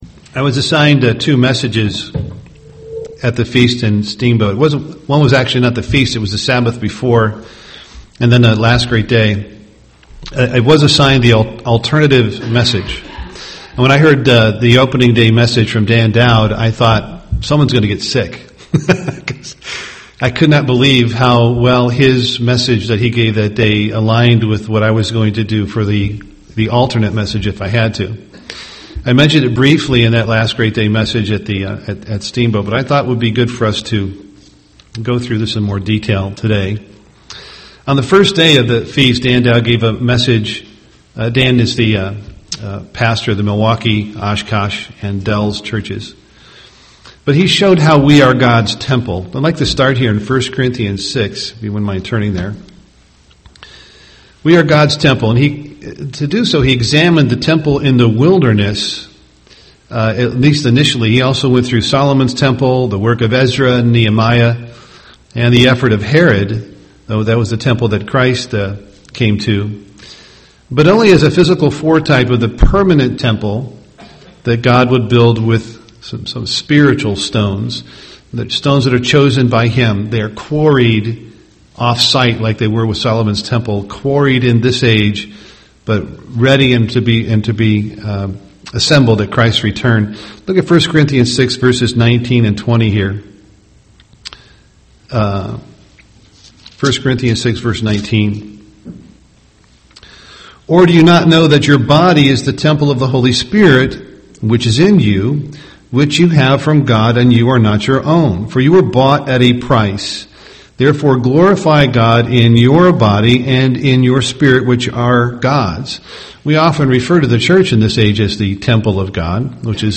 UCG Sermon glory spiritual temple of God Studying the bible?